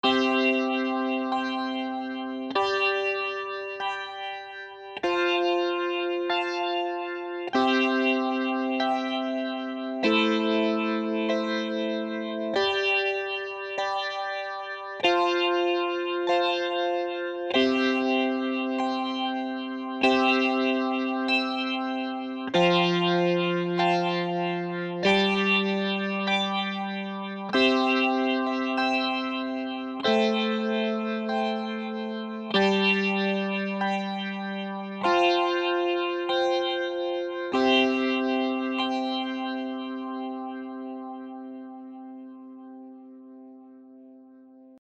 Weniger bissig gibt sich das Patch Electric Harpsichord – Warm, bei dem lediglich die Pickups in Betrieb sind. Phaser und Vibrato erzeugen angenehme Schwebungen:
Die Akkordfolge stammt aus Toontracks EZkeys, Country.